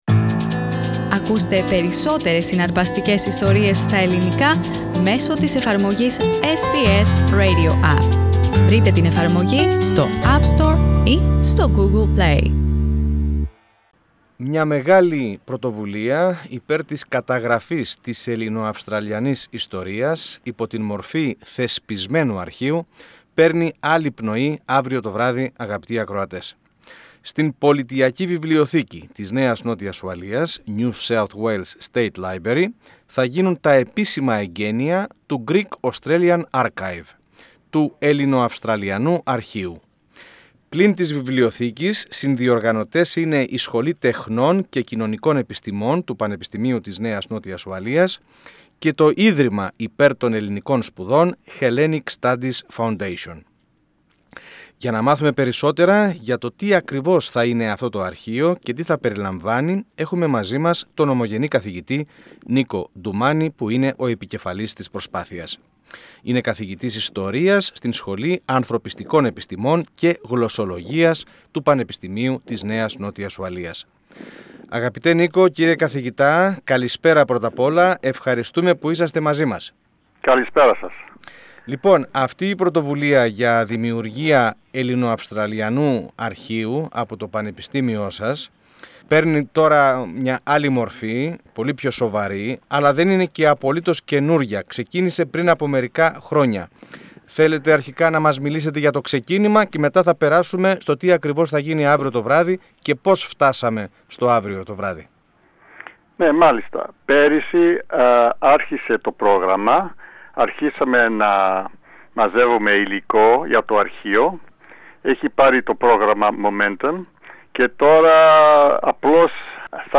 Πατήστε Play στο Podcast που συνοδεύει την κεντρική φωτογραφία για να ακούσετε τη συνέντευξη.